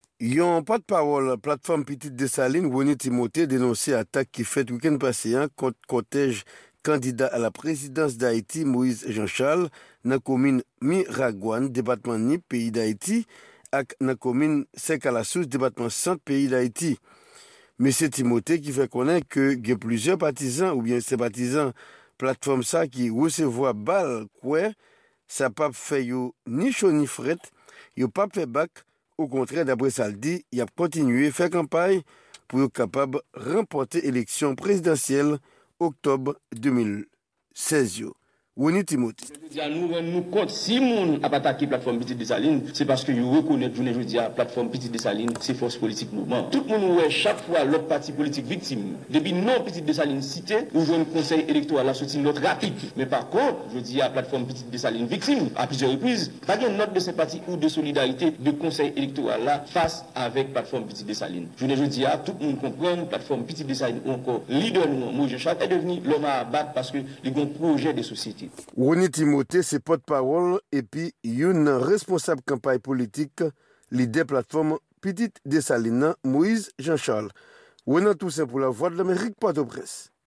Yon Repòtaj korespondan Lavwadlamerik